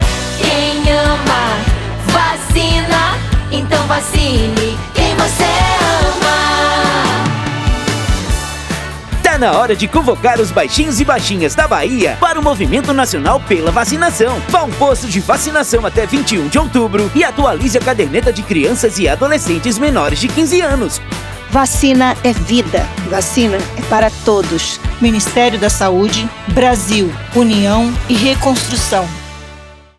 Áudio - Spot 30seg - Campanha de Multivacinação na Bahia - 1,1mb .mp3